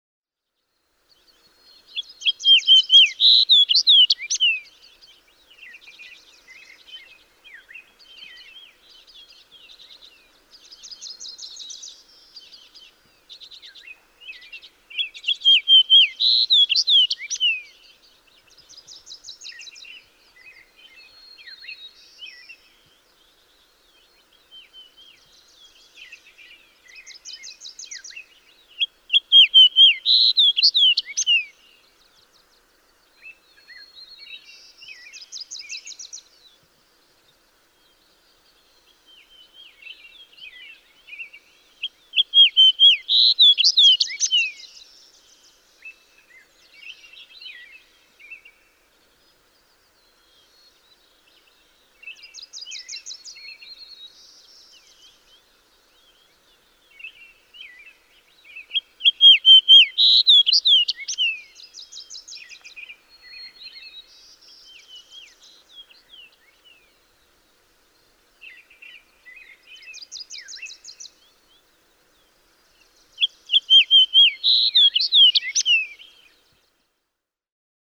Fox sparrow
284_Fox_Sparrow.mp3